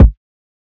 Taco Kick.wav